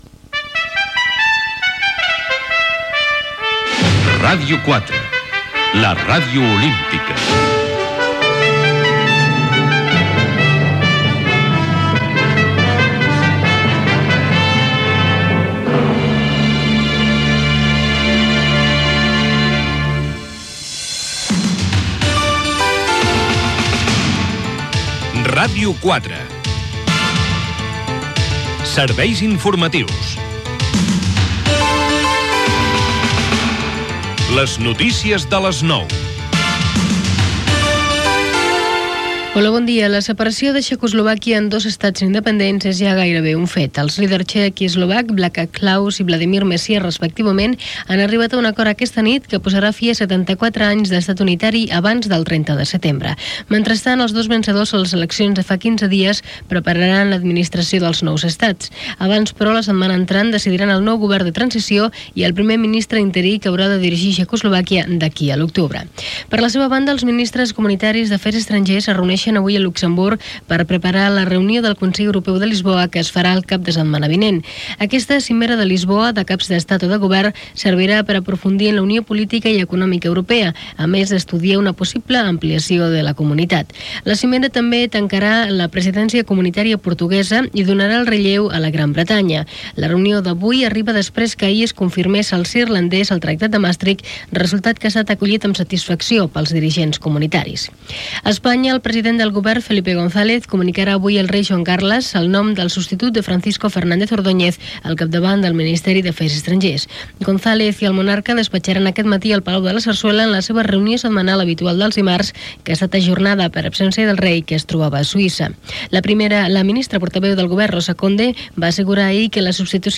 Indicatiu de Ràdio 4, la Ràdio Olímpica. Butlletí: separació de Txèquia i Eslovàquia, nou ministre espanyol d'afers estrangers, detenció d'un escamot d'ETA, reunió del comitè federal del PSOE, la flama olímpica arribarà a Lleida, les noves bases de Manresa.
Informatiu